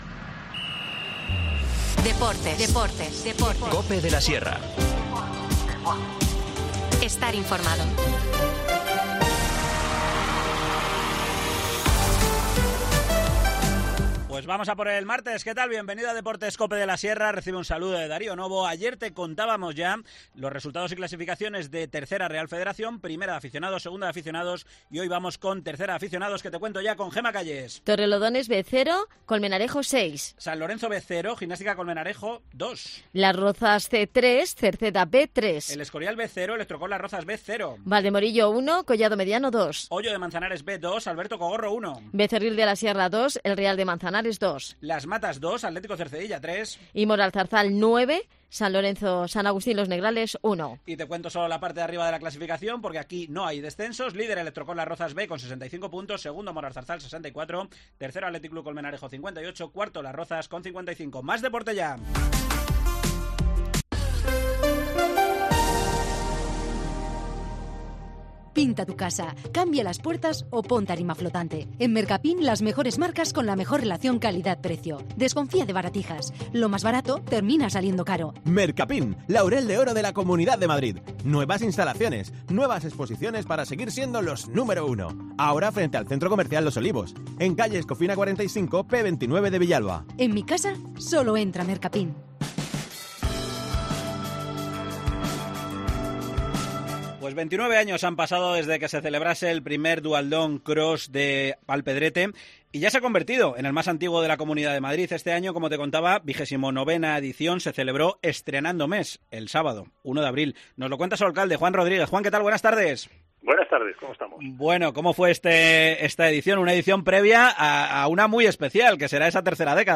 Alpedrete ha celebrado este fin de semana su duatlón que lleva en marcha 29 ediciones y que es el más antiguo de la región. Una edición con baja participación por la cercanía con los festivos de Semana Santa que nos detalla el alcalde alpedreteño, Juan Rodríguez.